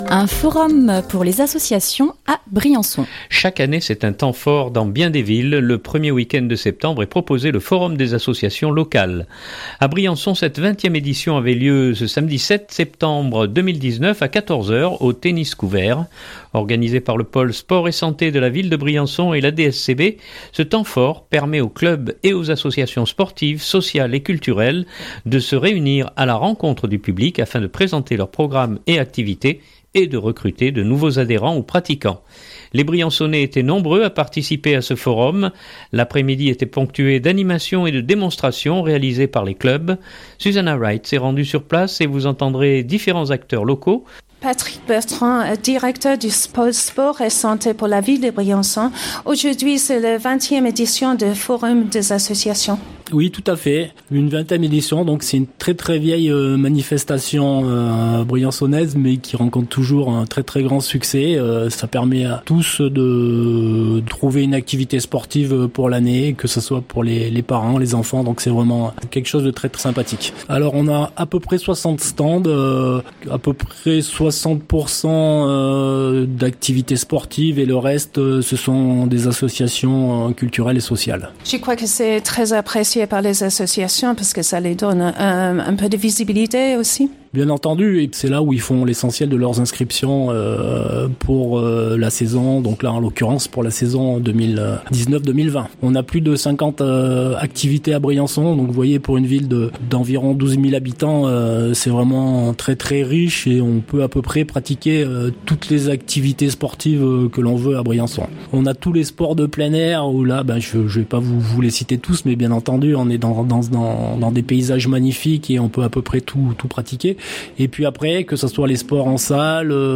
À Briançon cette 20ème édition avait lieu ce samedi 7 septembre 2019 à 14h aux tennis couverts. Organisé par le Pôle Sport & Santé de la Ville de Briançon et l’ADSCB, ce temps fort permet aux clubs et aux associations sportives, sociales et culturelles de se réunir à la rencontre du public afin de présenter leurs programmes et activités, et de recruter de nouveaux adhérents ou pratiquants.